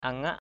/a-ŋaʔ/ (cv.) anangap anZP [A, 12]